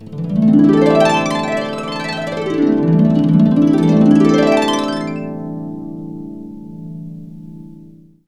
HARP DNX ARP.wav